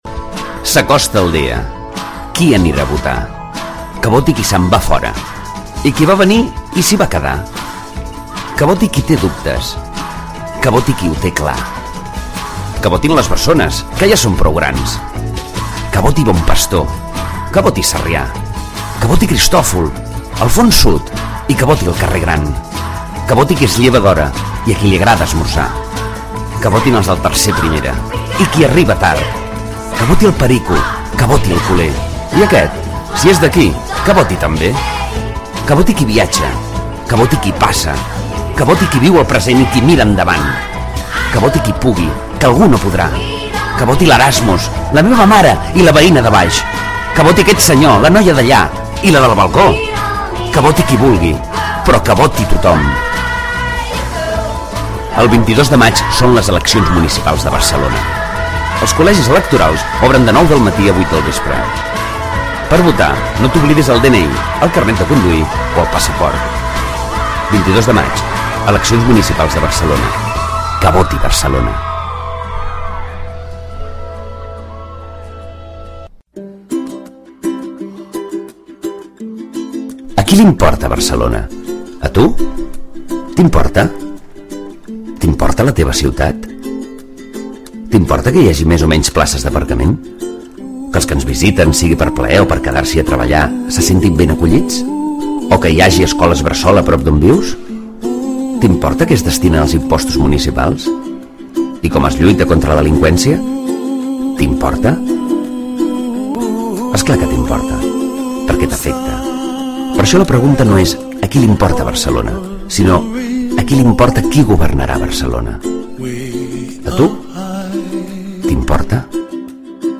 Eleccions municipals de Barcelona 2015. (Falca de ràdio)